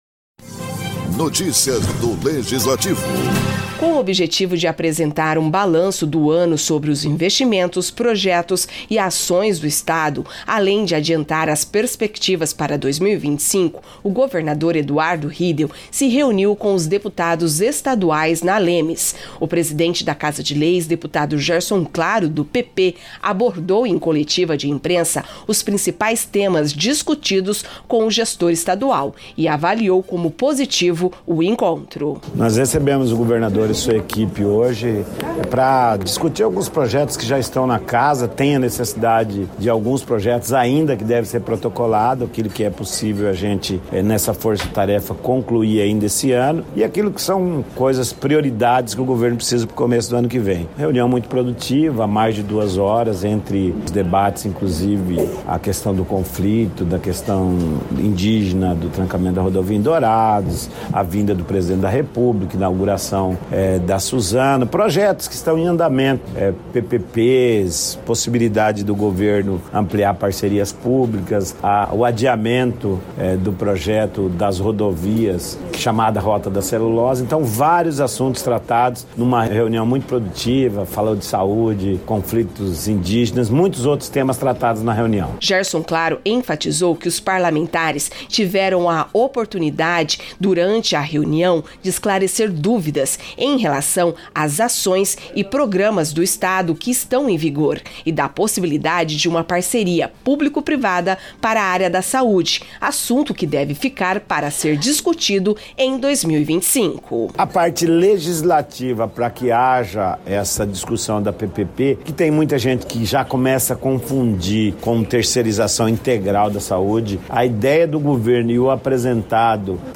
Com objetivo de apresentar um balanço do ano sobre os investimentos, projetos e ações do Estado, além de adiantar as perspectivas para 2025, o governador Eduardo Riedel se reuniu com os deputados estaduais, na Assembleia Legislativa de Mato Grosso do Sul (ALEMS).